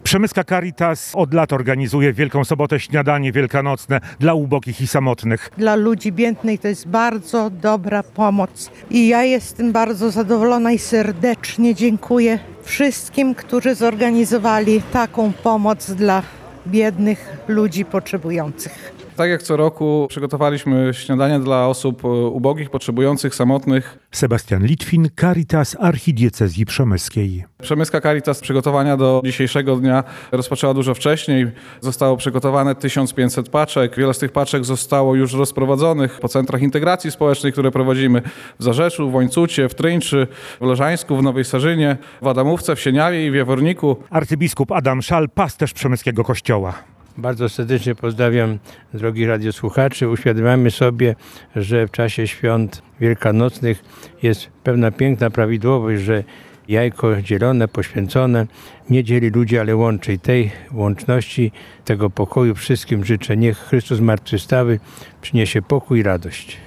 – mówił do zebranych arcybiskup Adam Szal, pasterz przemyskiego Kościoła.